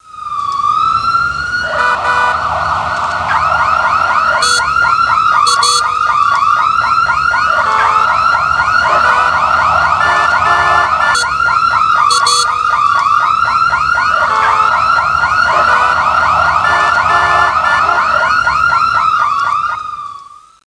traffic.mp3